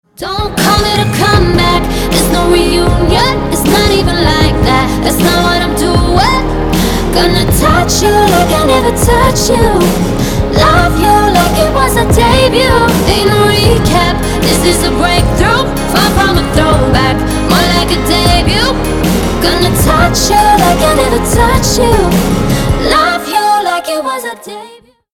• Качество: 320, Stereo
поп
женский вокал
dance
vocal